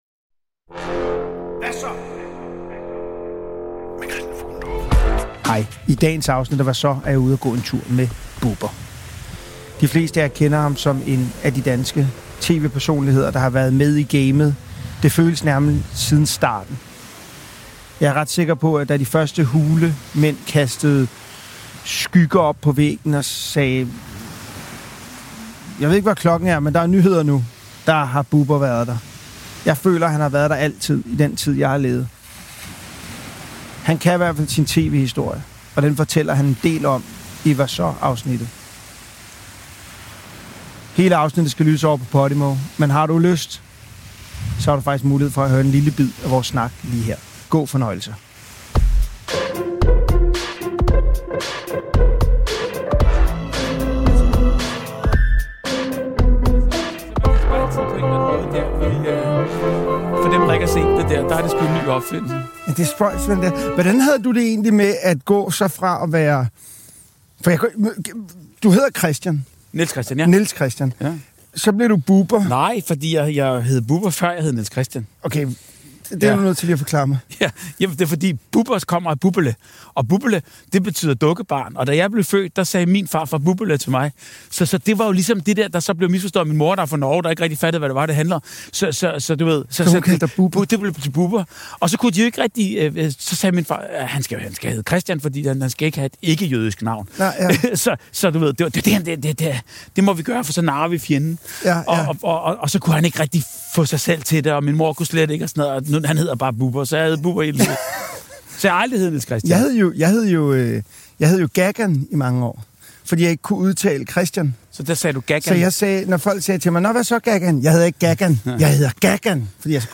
Genres: Comedy, Comedy Interviews, Society & Culture